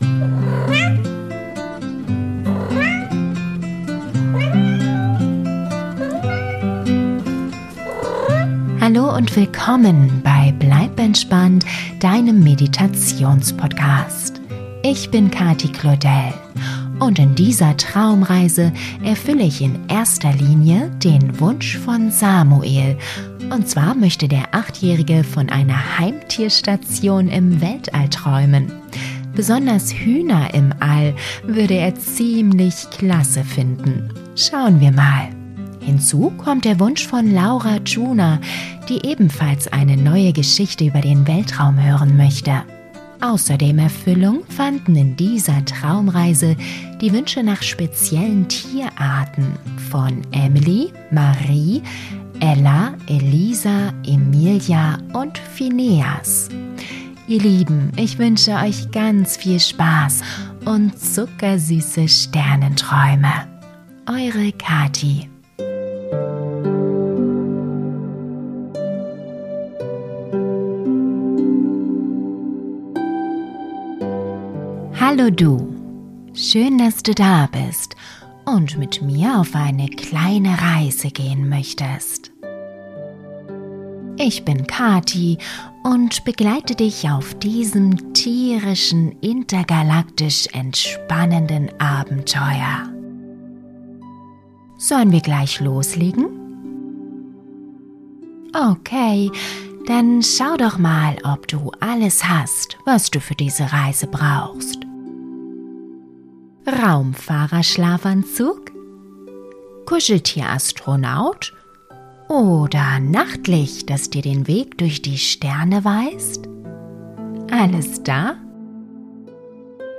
Doch gleichzeitig unheimlich beruhigend . Die sanfte Erzählung und die friedliche Atmosphäre schaffen einen Ort der tiefen Entspannung und Geborgenheit. Umgeben von leuchtenden Sternen und den niedlichsten Tieren kann dein Kind zur Ruhe kommen und seine Alltagssorgen loslassen .